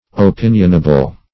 Opinionable \O*pin"ion*a*ble\, a.